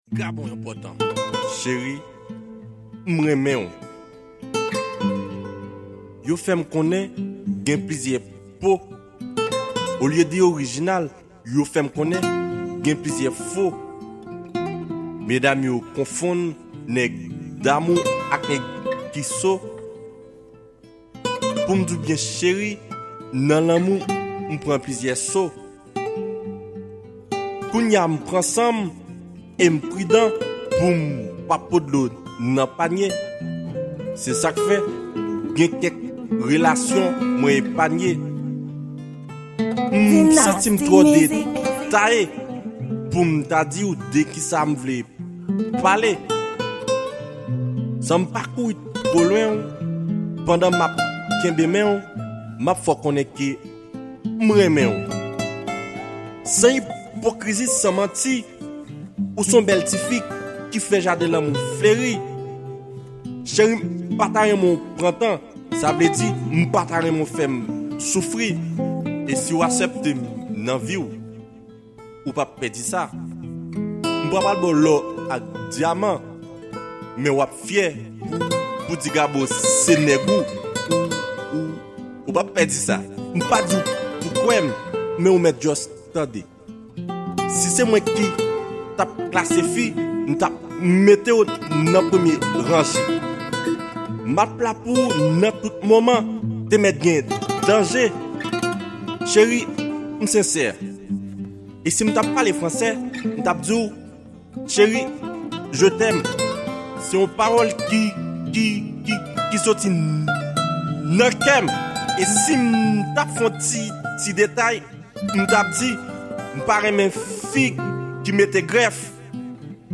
Genre: Slam.